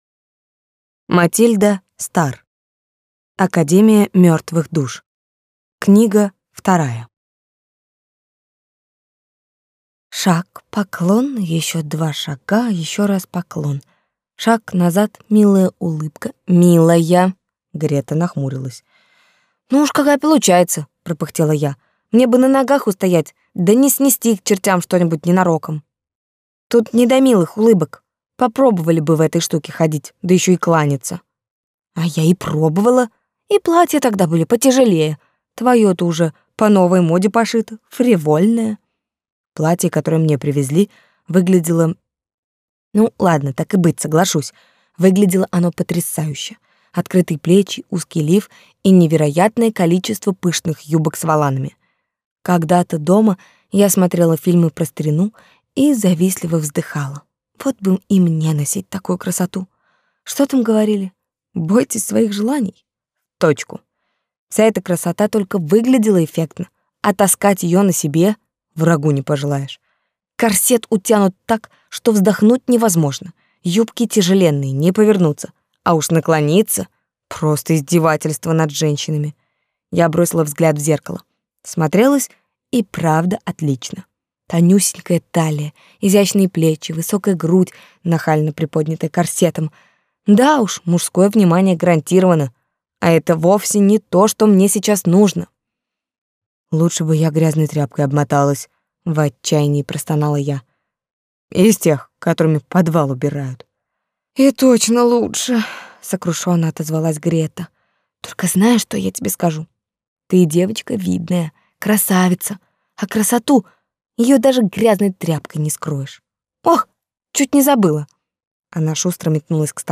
Аудиокнига Академия мертвых душ.